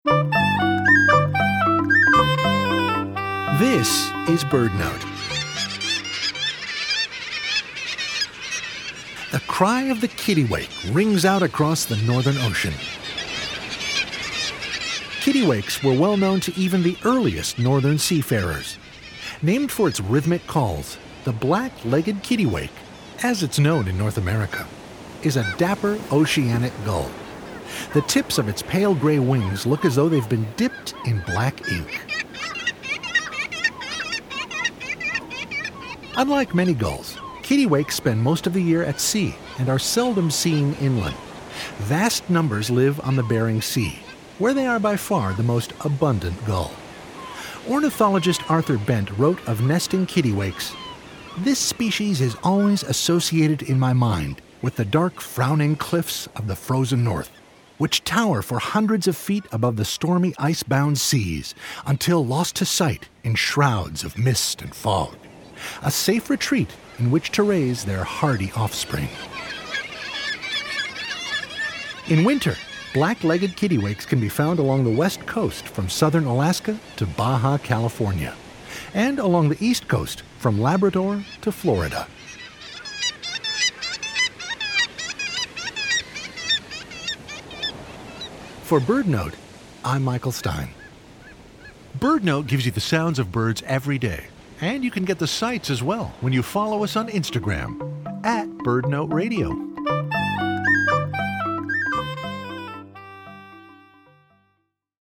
Named for its rhythmic calls, the Black-legged Kittiwake is a dapper, oceanic gull.